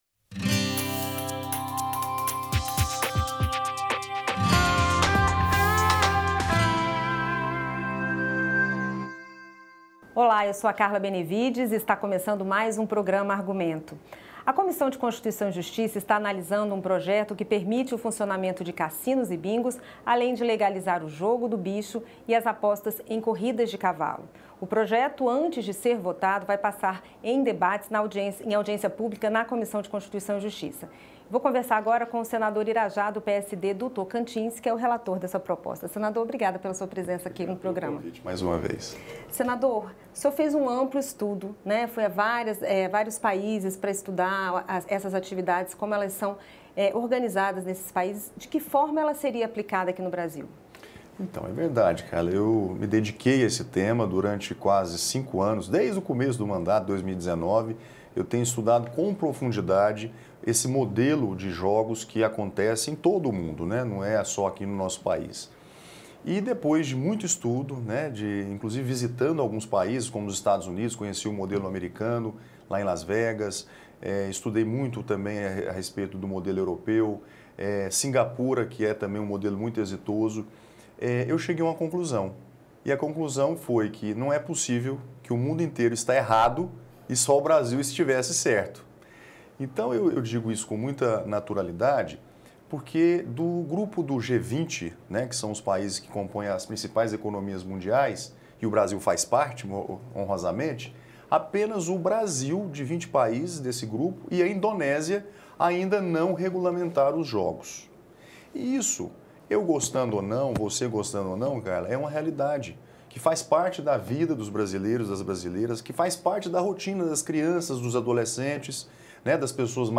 Entrevistas com senadores sobre temas de relevância nacional
Programa de entrevistas com temas de relevância nacional na opinião de líderes partidários, presidentes das comissões, autores e relatores de projetos.